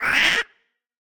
Minecraft Version Minecraft Version snapshot Latest Release | Latest Snapshot snapshot / assets / minecraft / sounds / mob / ghastling / hurt5.ogg Compare With Compare With Latest Release | Latest Snapshot
hurt5.ogg